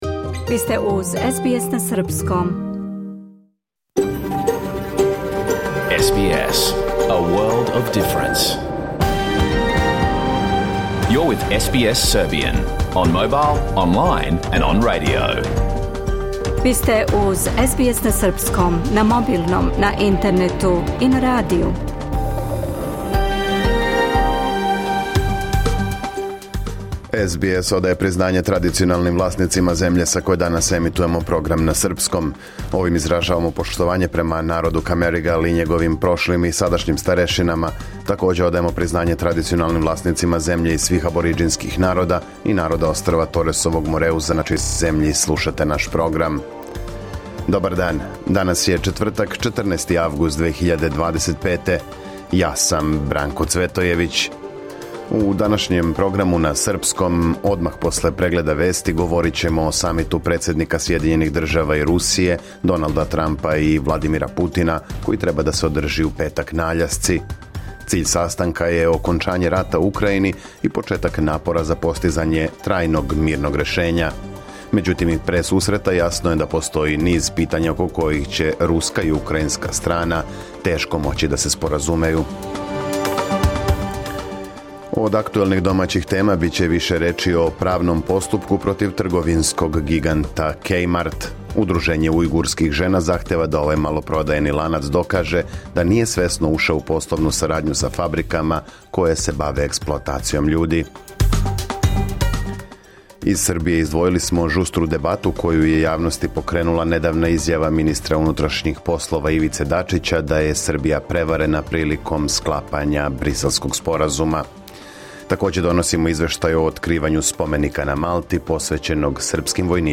Програм емитован уживо 14. августа 2025. године